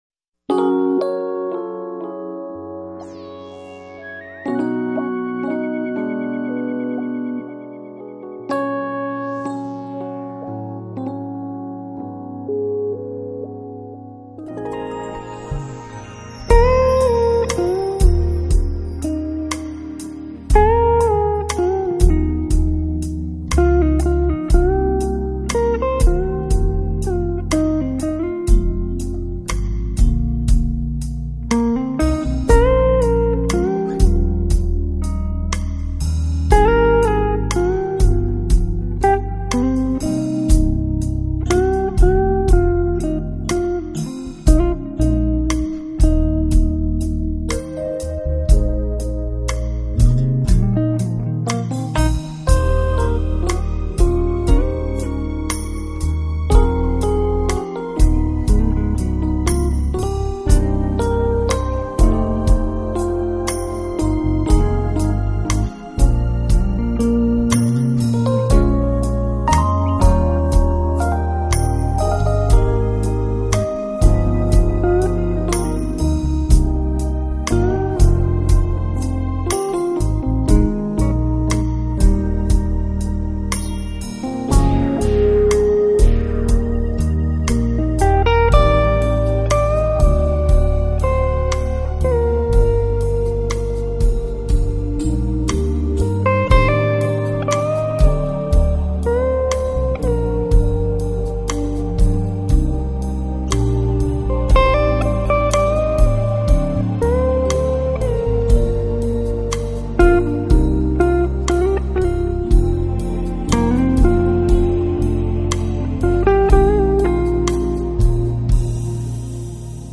【浪漫爵士乐】